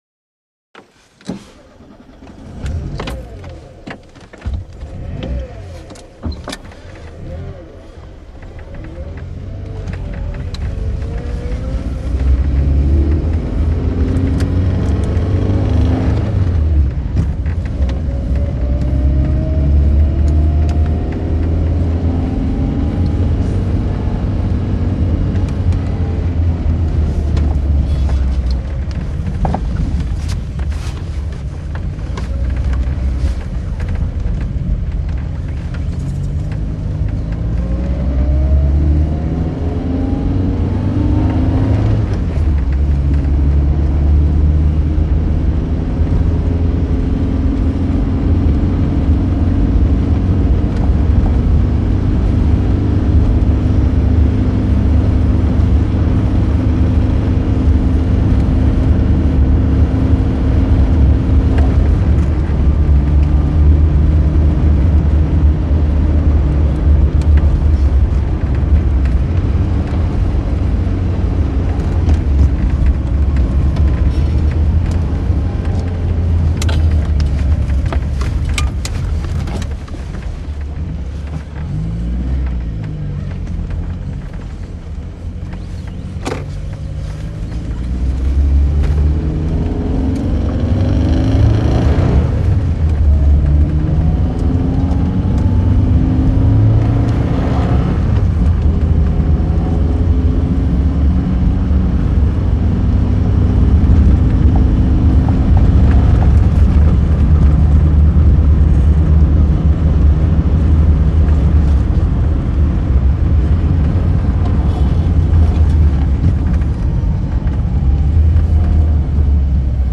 Automobile; Interior ( Constant ); Interior Audi 80l Start Up And Away With General Driving Around, Eventually Stops Idling And Switch Off.